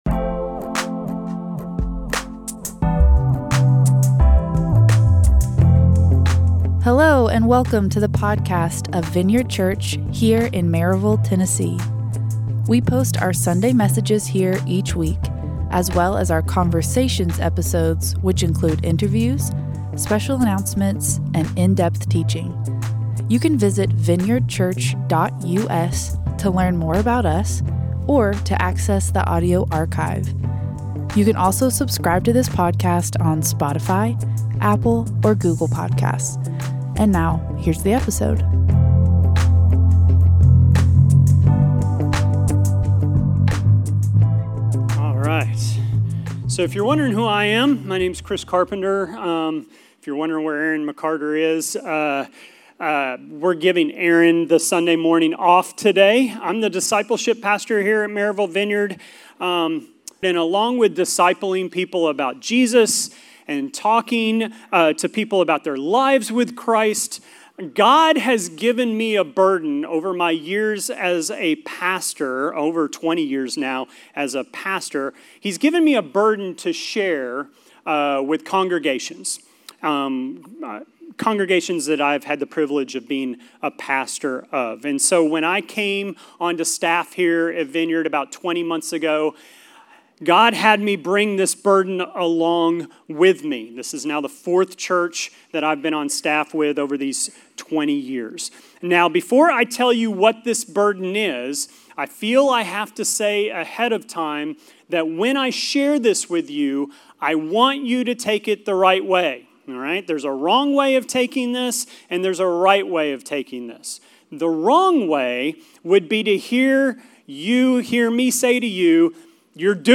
A message from the series "Family." Do we still see the church today as a family, or have we allowed our individualistic culture to change our viewpoint of the church? How do we keep the family atmosphere of the church body?